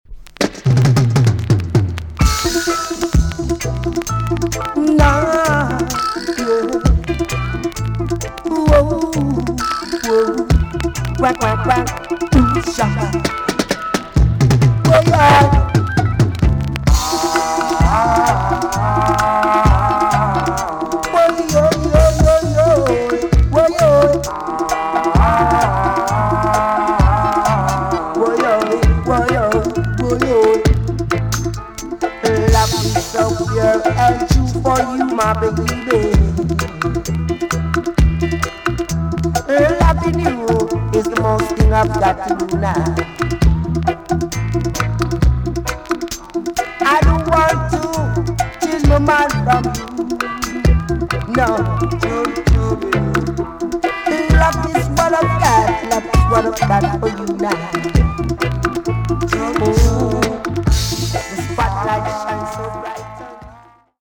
TOP >REGGAE & ROOTS
VG+ 軽いチリノイズがあります。
KILLER ROOTS TUNE!!